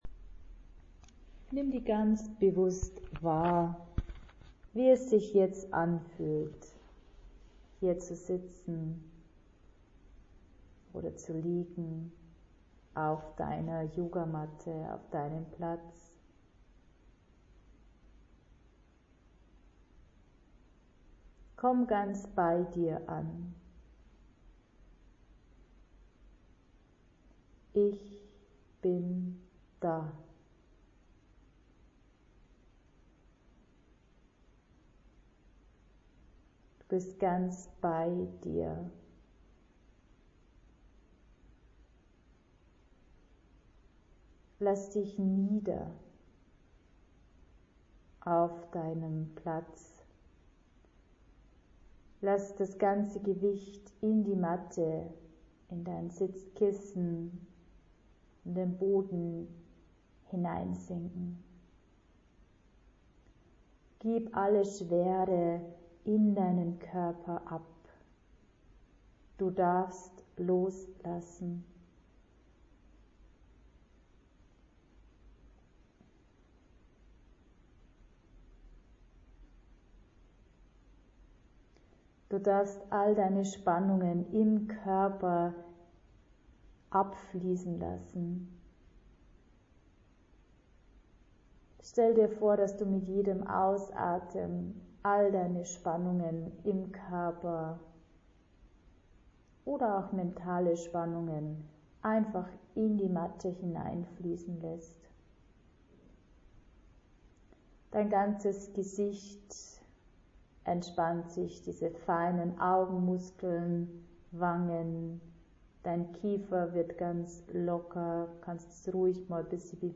NEU: Friedensmeditation
2022_-Friedensmeditation.mp3